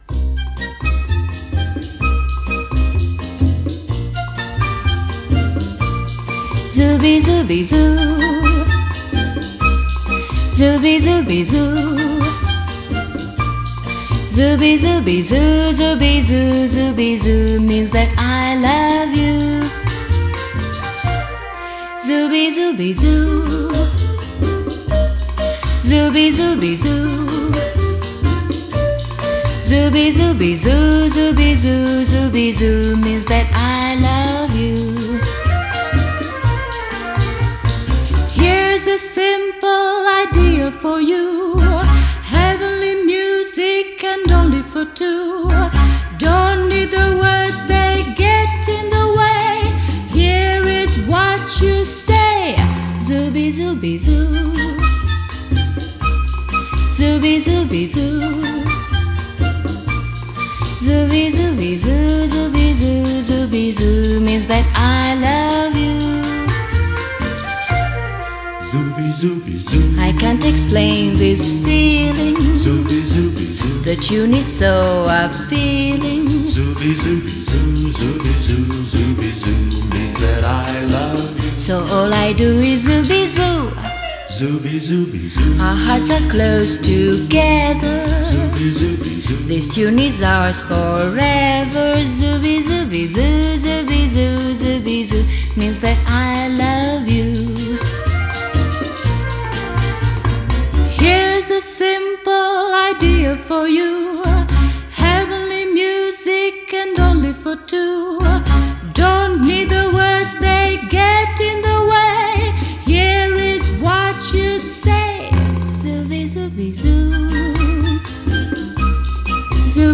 Genre: Pop, Jazz, Soundtrack, Instrumental, Divers, Italian